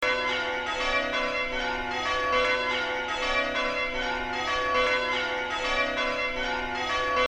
Spires and towers often contain church bells.
angbells.mp3